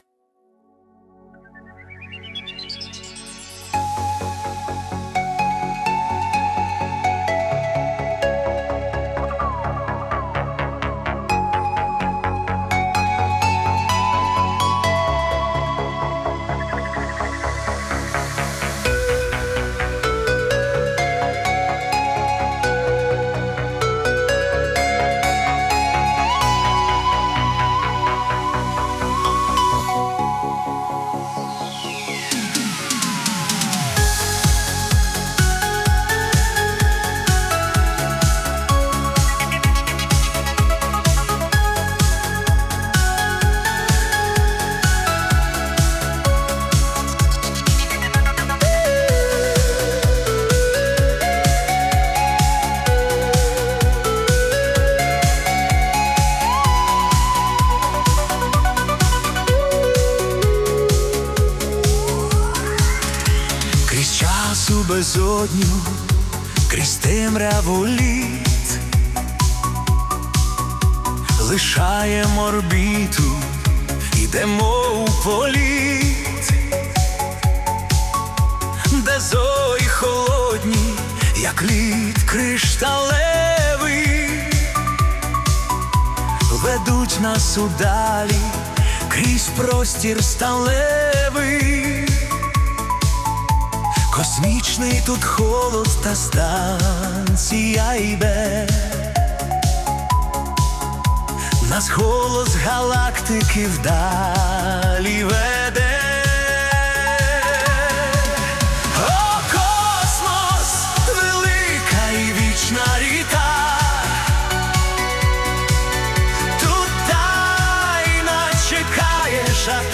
Space Ambient / Sci-Fi